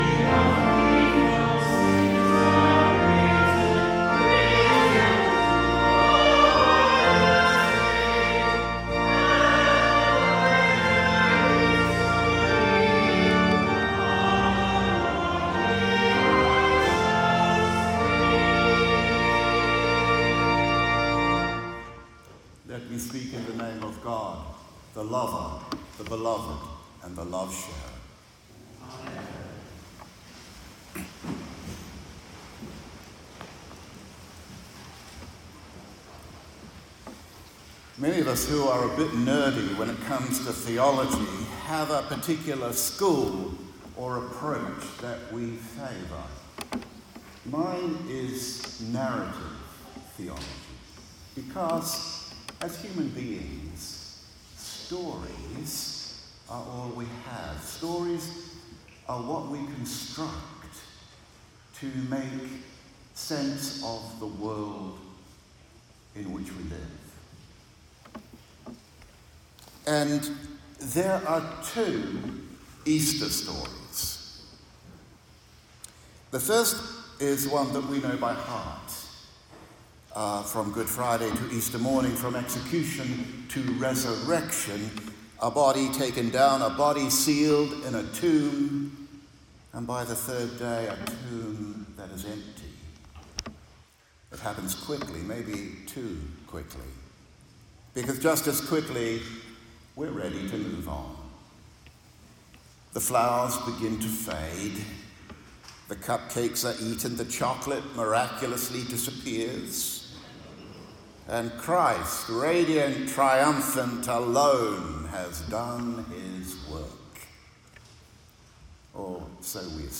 Sermon Audio: content reinterpreted for oral delivery.